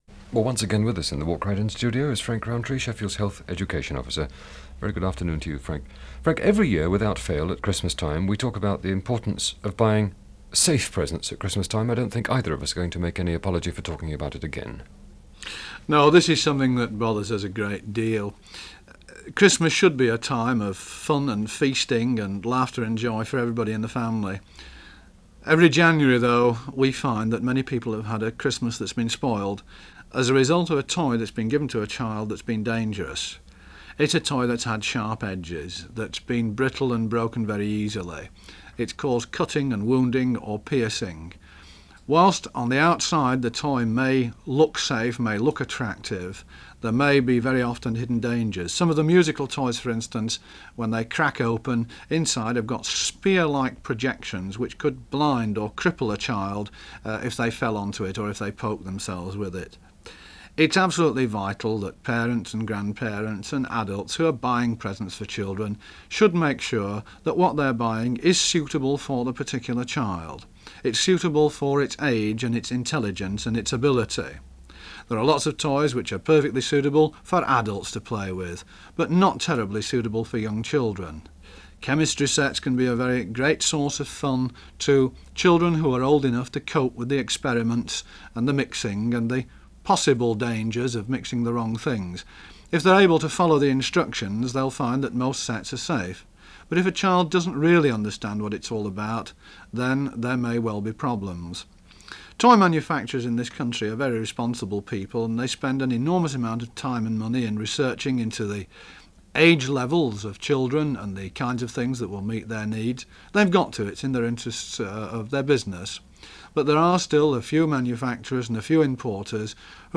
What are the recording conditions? Sheffield : BBC Radio Sheffield, 1972. He was invited into the BBC Radio Sheffield studios for a regular short weekly health spot.